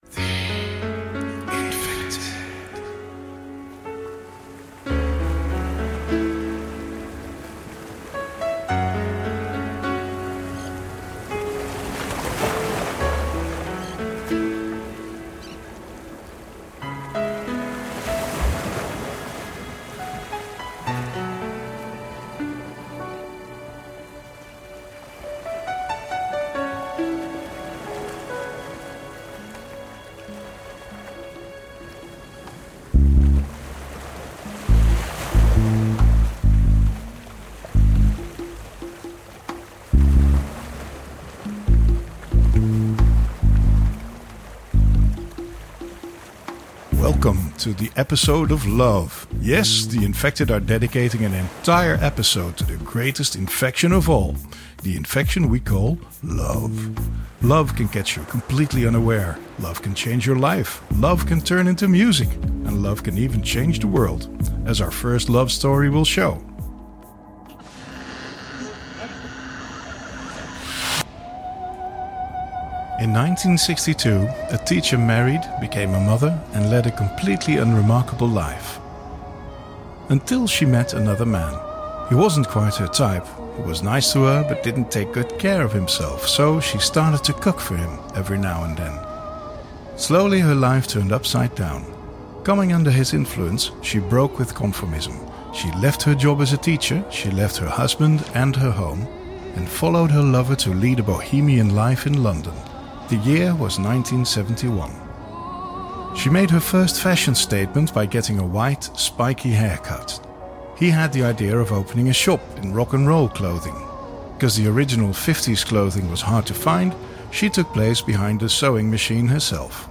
Lovers Dressed In Black The Infected: Post-Punk, Alternative, New Wave, Goth Music Podcast With Background Stories And Tips podcast